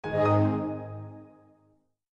Звуки приветствия Windows